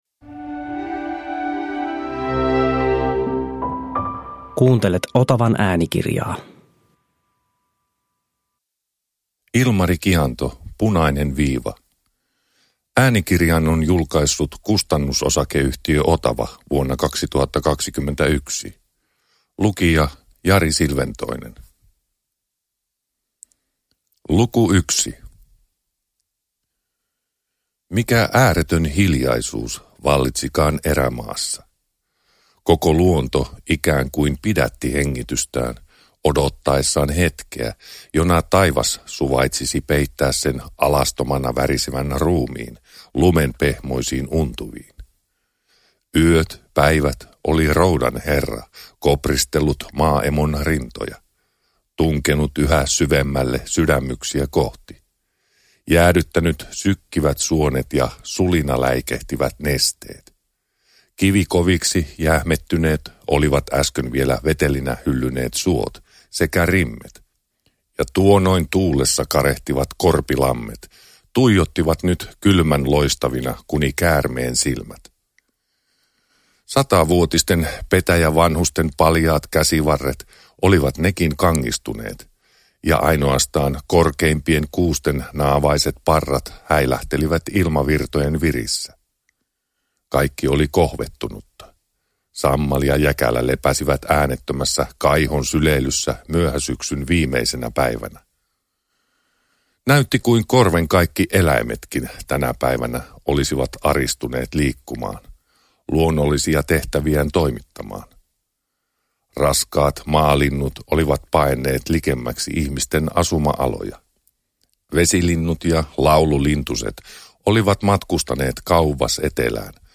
Punainen viiva – Ljudbok – Laddas ner